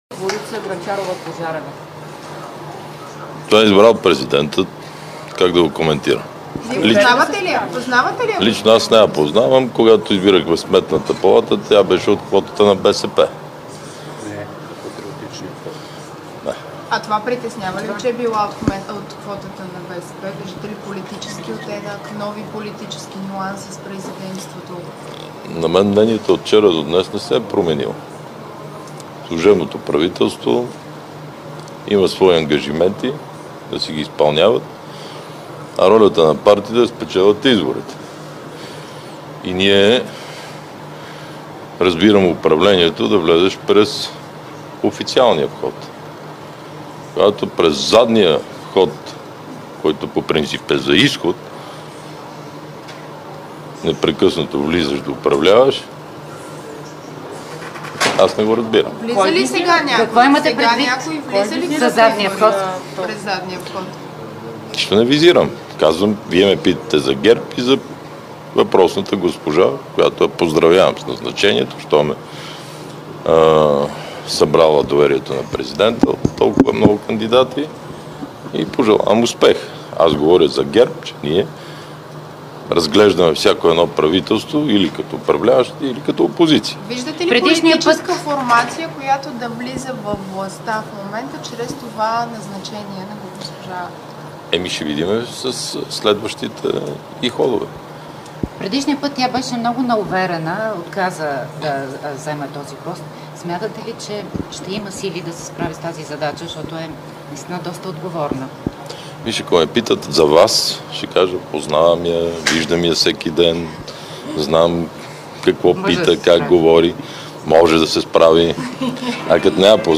9.05 - Заседание на Народното събрание.
Директно от мястото на събитието